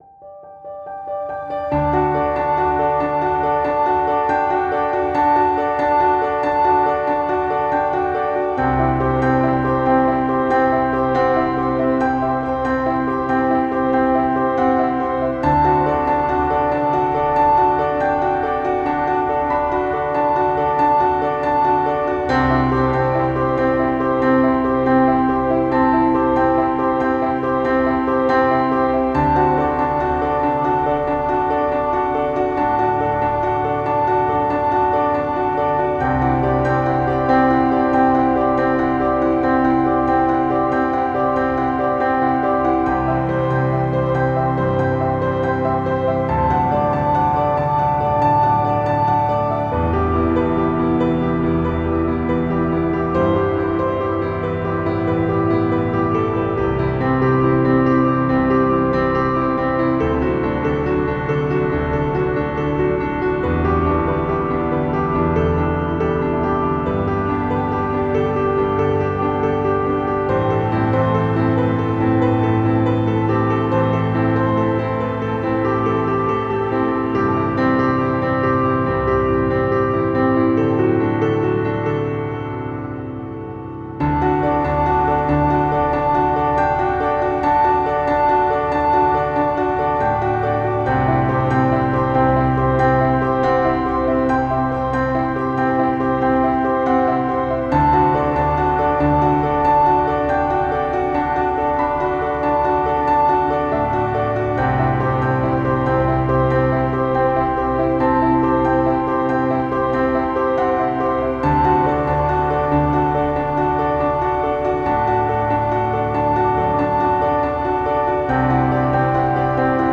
"Ambient Background"